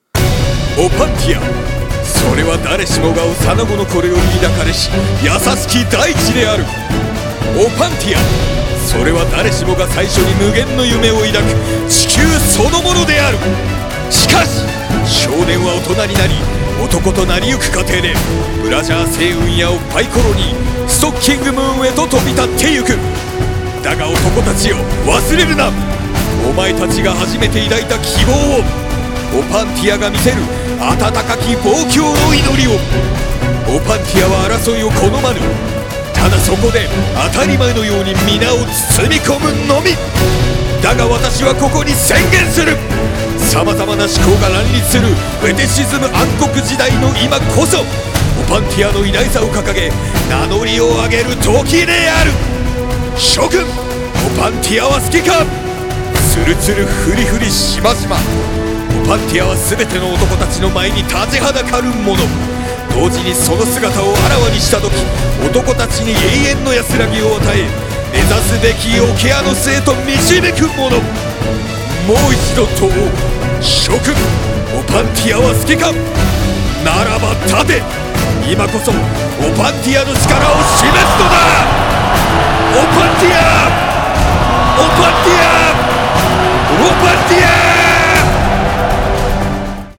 オパンティア大戦開戦前夜の演説記録（一人声劇